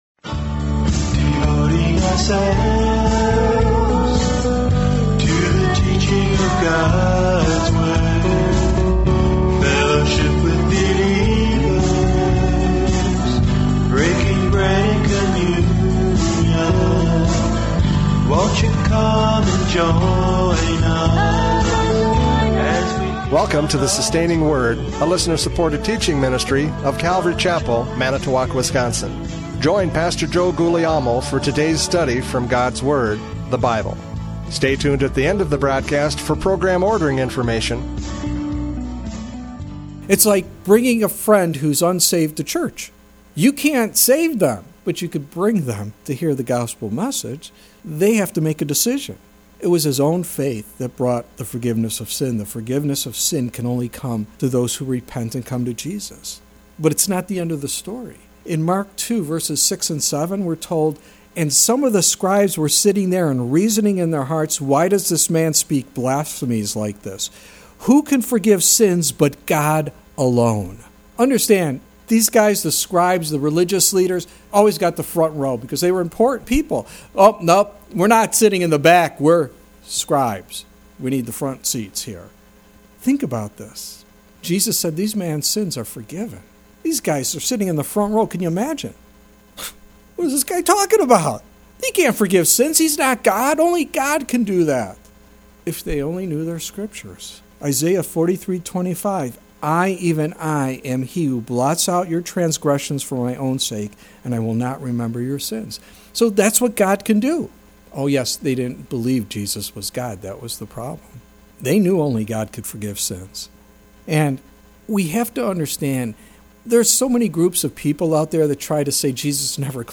John 5:36 Service Type: Radio Programs « John 5:36 Testimony of Miracles!